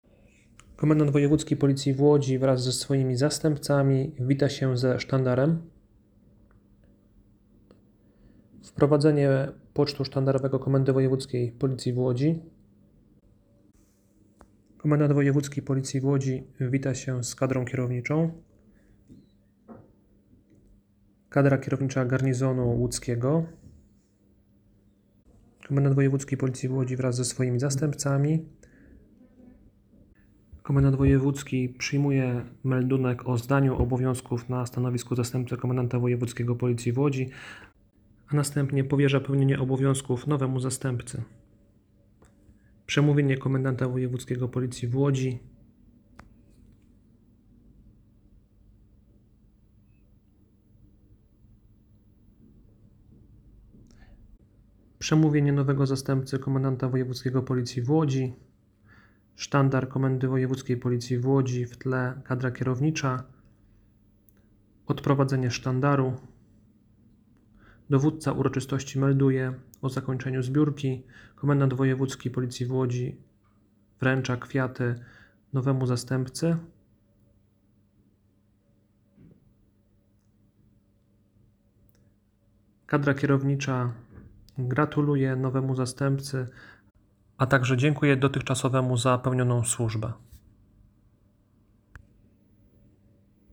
20 listopada 2024 roku, w Auli im. nadinspektora Henryka Wardęskiego Komendy Wojewódzkiej Policji w Łodzi, odbyła się uroczysta zbiórka, podczas której powierzono pełnienie obowiązków na stanowisku Zastępcy Komendanta Wojewódzkiego Policji w Łodzi mł. insp. Anicie Tim – Warchał, dotychczasowemu Naczelnikowi Wydziału Gospodarki Materiałowo – Technicznej KWP w Łodzi.